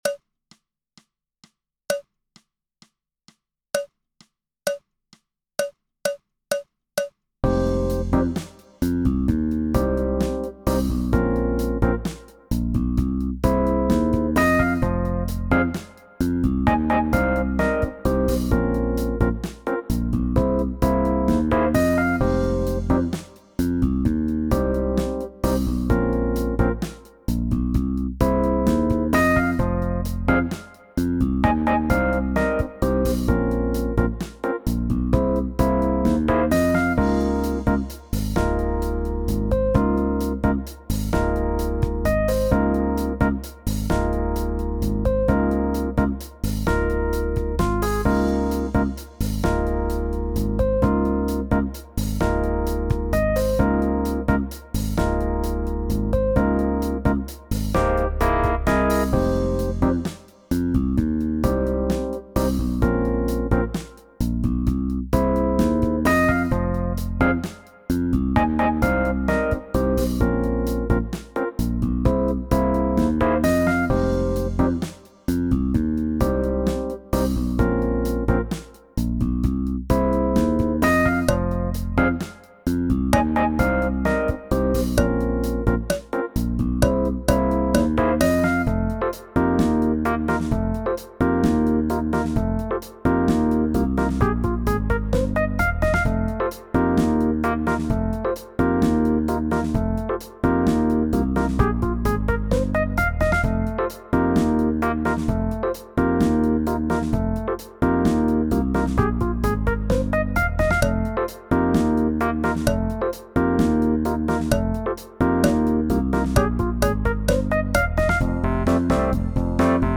Snare Drum Warmup
It covers many fundamental techniques and is fun to play! Note: In the track, the cowbell cues the next exercise.